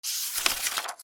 / M｜他分類 / L01 ｜小道具 / 文房具・工作道具
ページをめくる(本)